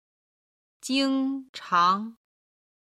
经常　(jīng cháng)　いつも